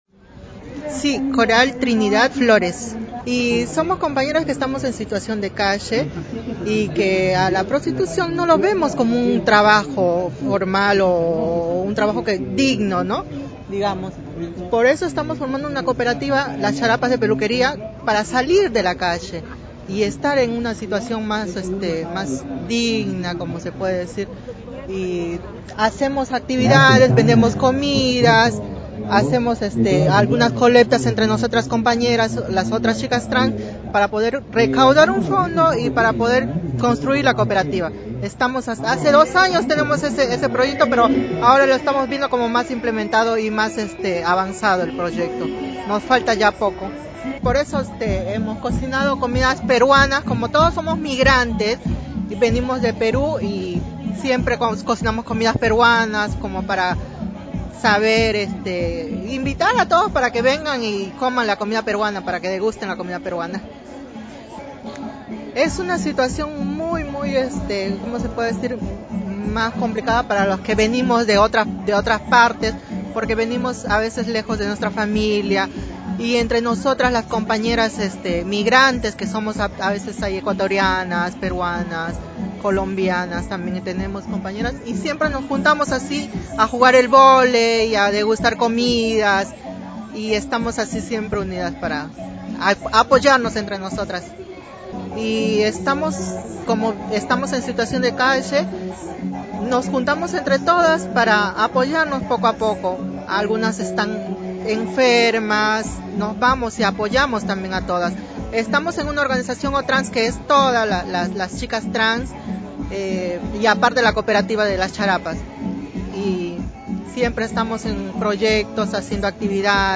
Se realizó el festival por los derechos trans en Plaza Matheu